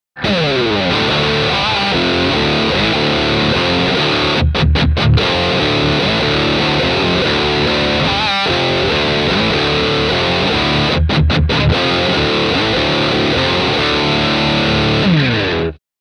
High Gain Traditional Distortion
Unlikely normals, the TRANSFORM control has deep, colorful and wide range of drive sound.
Demo with Humbucker Pickup